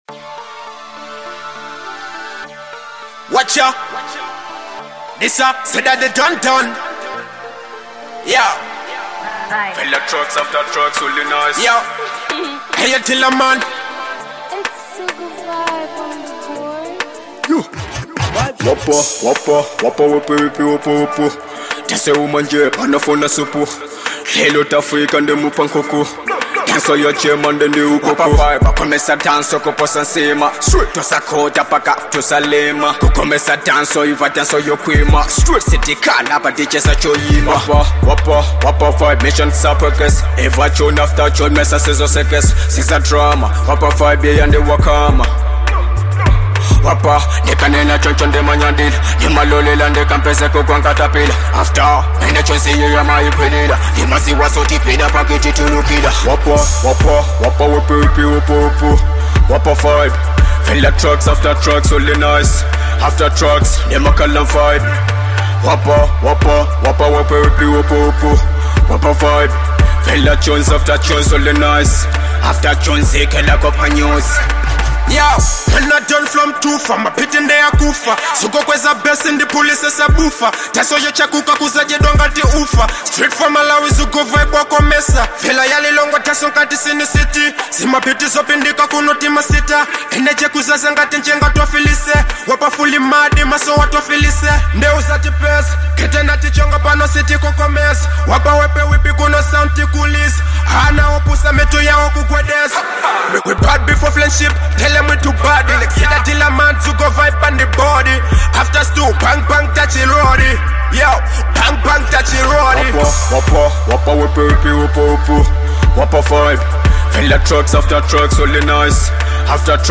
Genre : Dancehall
hard-hitting trap banger
blending street vibes and raw energy.